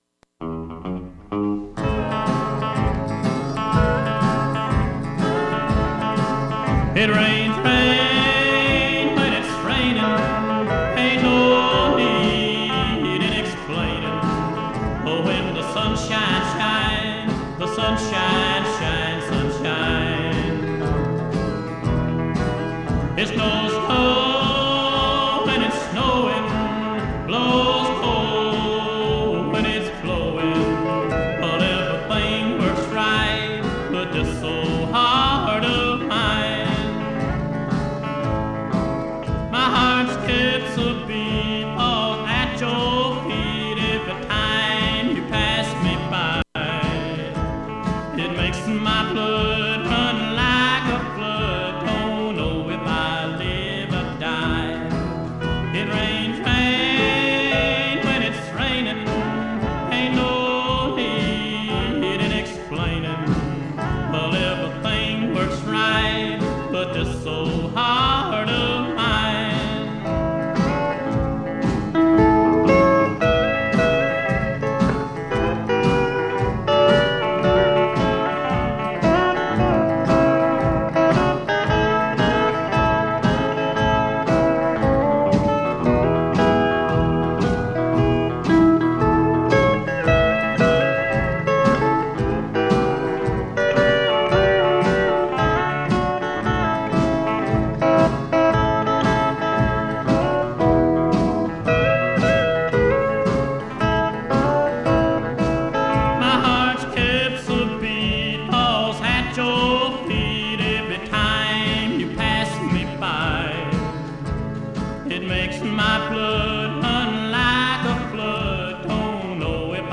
You’re invited to put on your boots and join us as we meander through the wild areas of our modern urban landscape, exploring contemporary and classic Americana, folk, country and elusive material that defies genre. New or artifact, urban or rural – City Folk is a curated field guide for the humble, a study in dirt and bone.